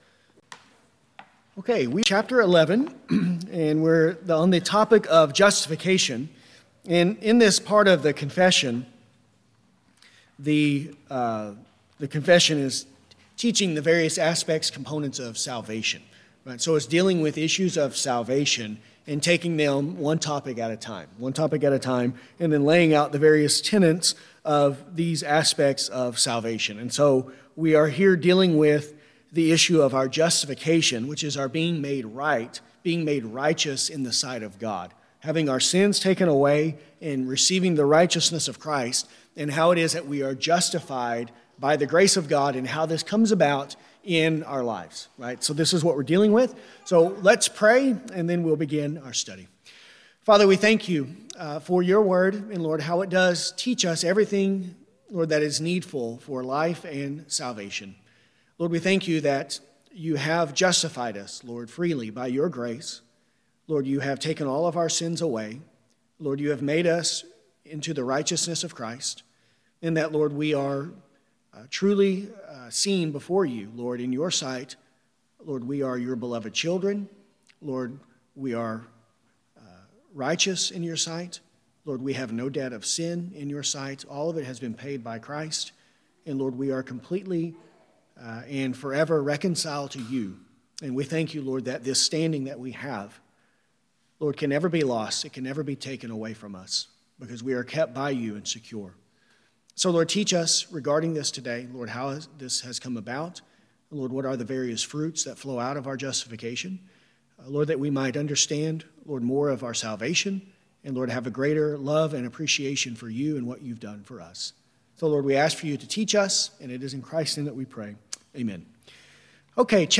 This lesson covers Paragraphs 11.2-11.3.